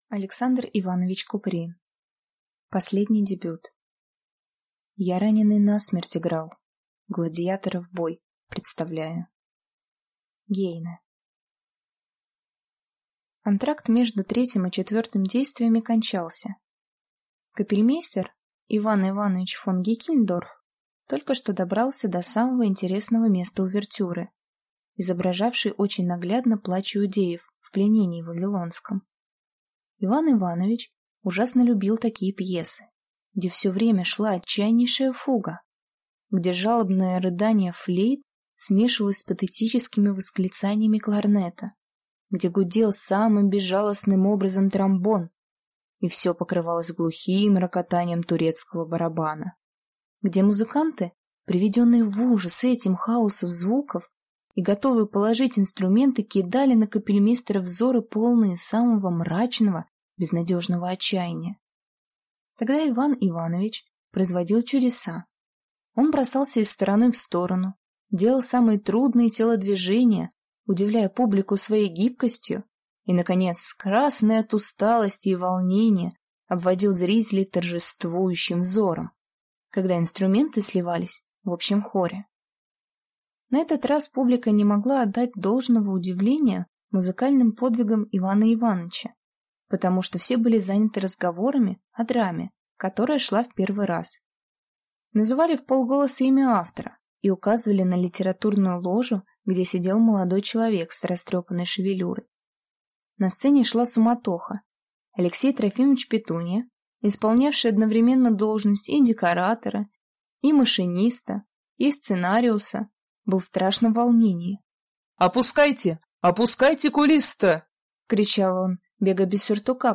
Аудиокнига Последний дебют | Библиотека аудиокниг